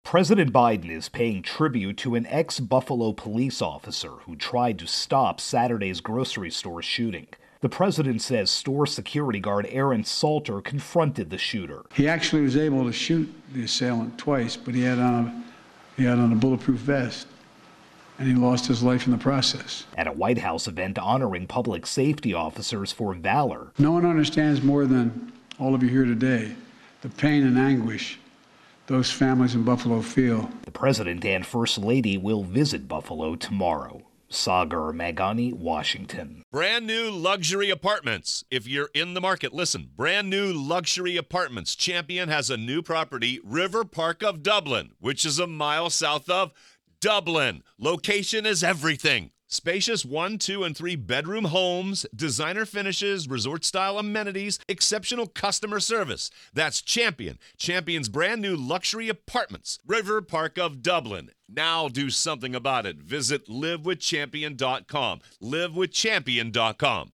Biden intro and wrap.